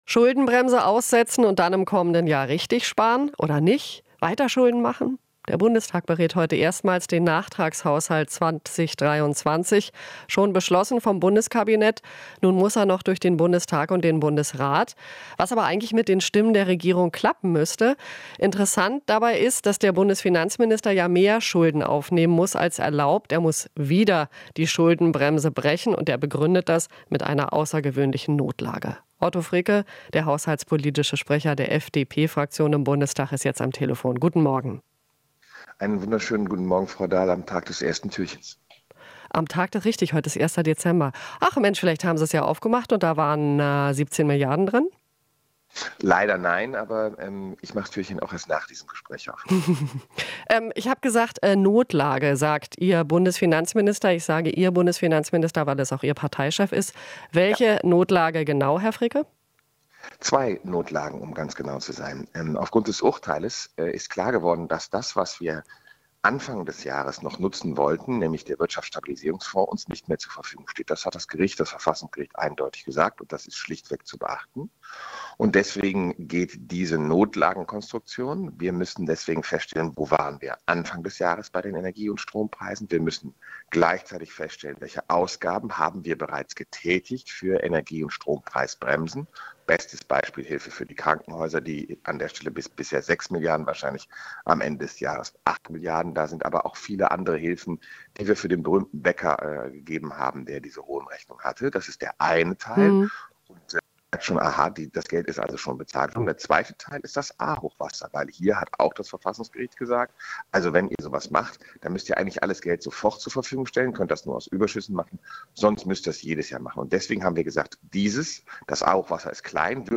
Interview - Fricke (FDP) mahnt Besonnenheit beim Haushalt an